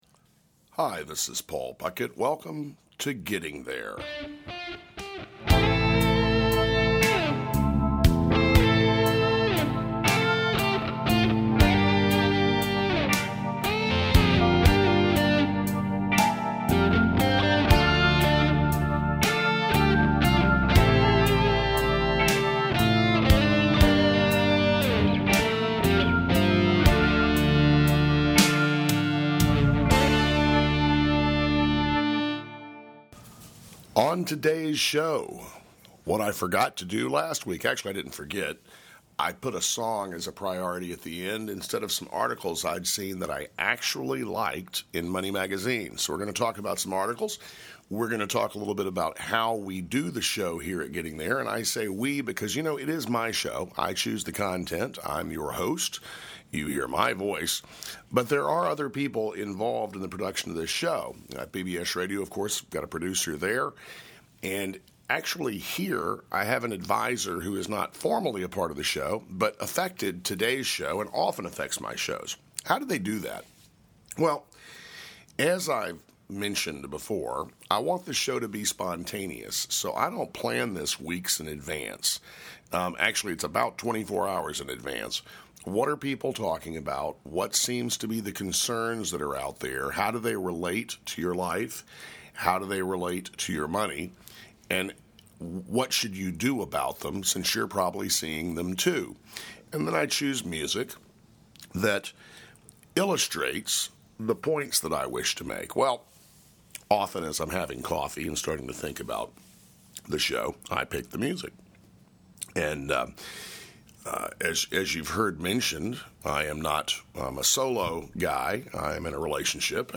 Talk Show Episode
In addition to money issues, the conversation will include gardening, cooking, books, travel, and interviews with guests from the financial field as well as chefs, gardeners, authors, and spiritual leaders.